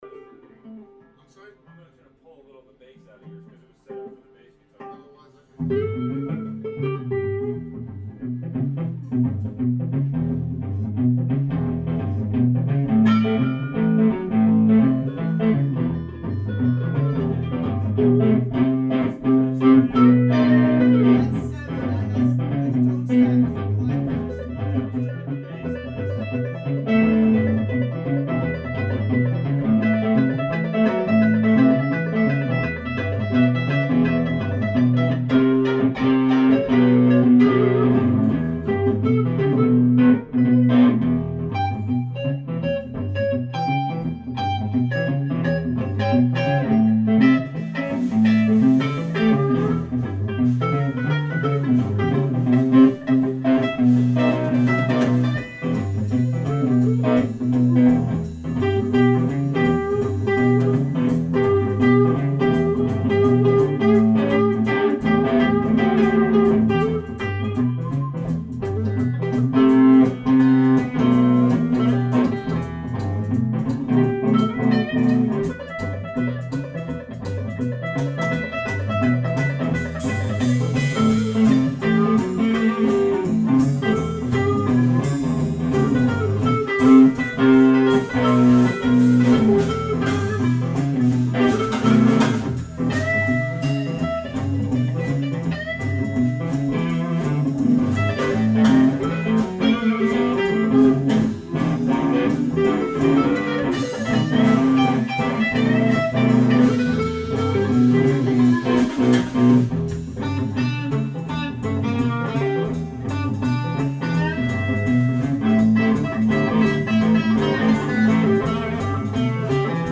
Session and guest musicians jam on GAGA and GAGA D-60 thru various speakers, cabinets, and power tube mixtures in a tasty, small band context.
Guitars and bass were all amplified by GAGA and GAGA D-60 through Jensen Tornado, Celestion Vintage30, and other 12" speakers.
Guest + Bass + GAGA